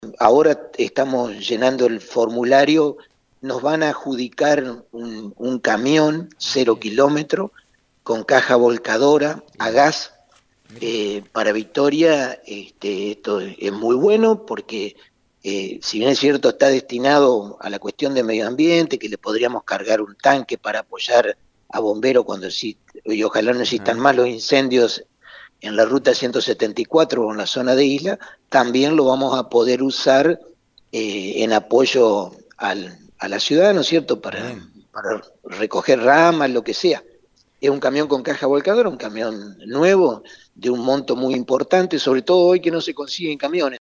La noticia fue confirmada por el intendente Domingo Maiocco en una entrevista para FM 90.3. La unidad será destinada a la recolección de grandes residuos y estará a disposición del Cuartel de Bomberos Voluntarios en los casos que lo requieran.